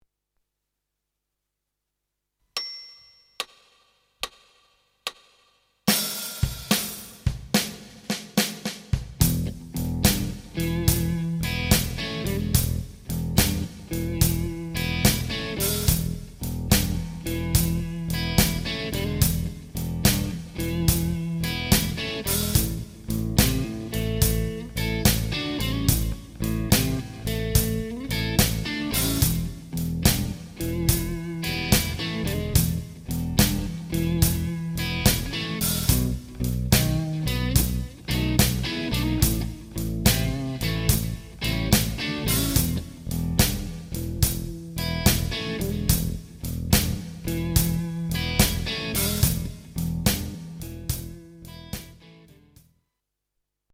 A blues zene alaplüktetése triolás.
/wp-content/uploads/blues-improvizacio-1-12.mp4 Zenei alap gyakorláshoz
blues-improvizacio-alap-1.mp3